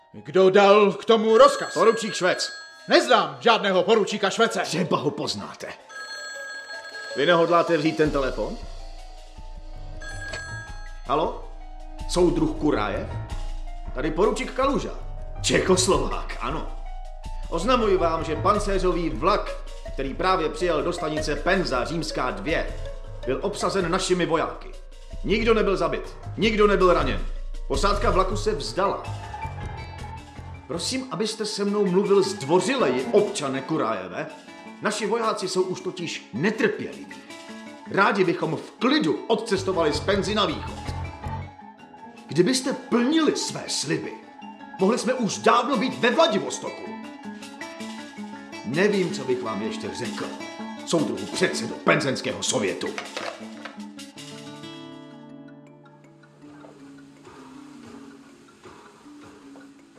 Audiobook
Read: Igor Bareš